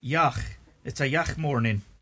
[yach: uts a yach MORnin]